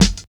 100 SNARE 2.wav